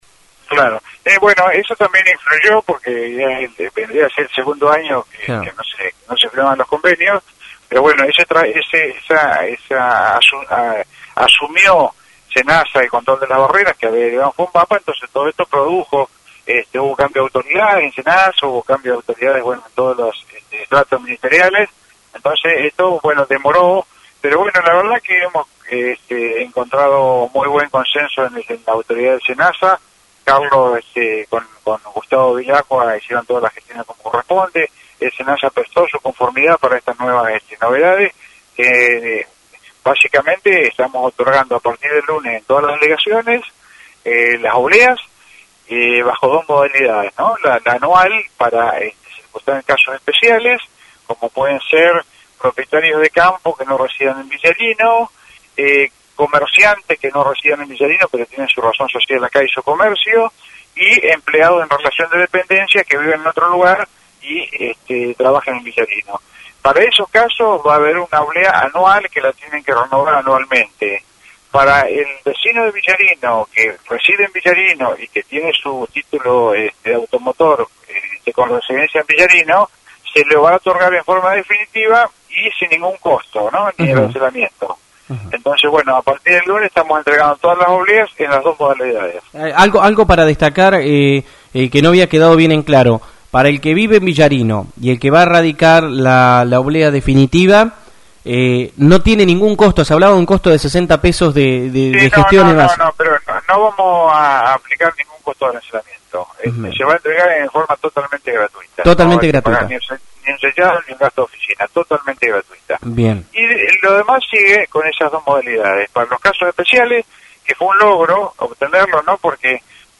Desde este lunes se entregaran las dos modalidades de oblea en cada delegación municipal. Al respecto esto decia Horacio Brion, Jefe de Gabinete de Villarno, a la Zfm.